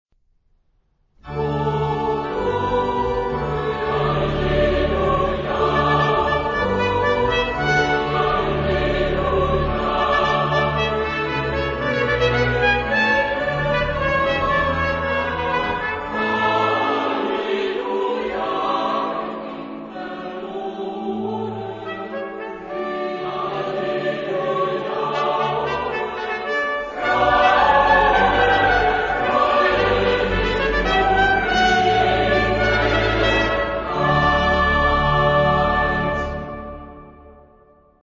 Genre-Style-Form: Sacred ; Christmas song
Mood of the piece: festive ; joyous
Instruments: Melody instrument (1) ; Keyboard (1)
Tonality: D major